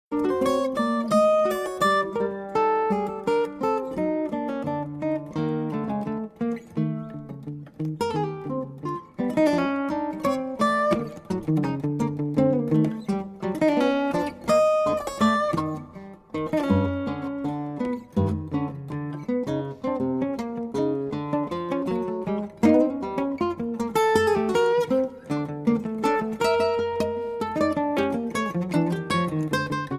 Two Master Guitarists + 18 tracks = Acoustic Improv Heaven